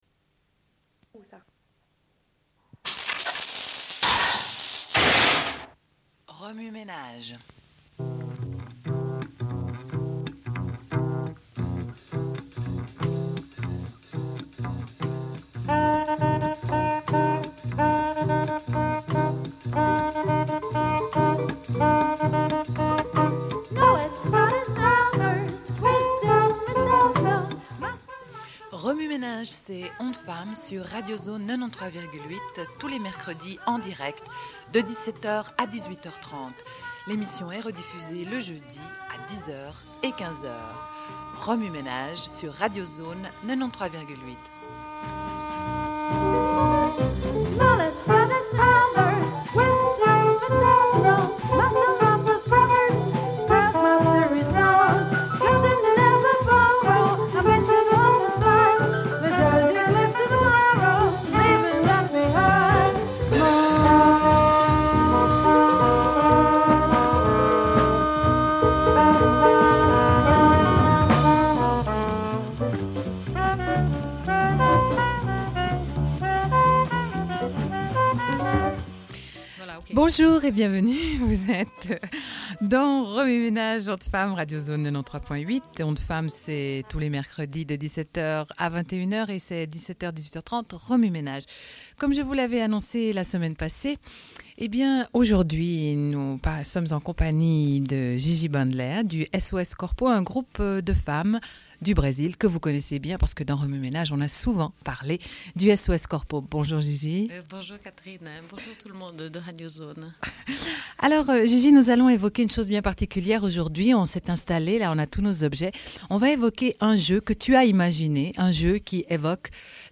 Une cassette audio, face A31:12